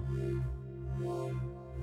elevator.wav